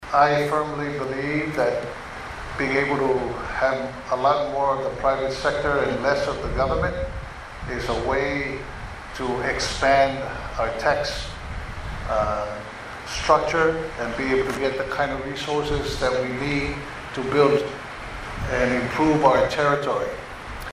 Governor Pulaalii Nikolao Pula has emphasized the importance of expanding the private sector as a means to attain greater economic development for the territory.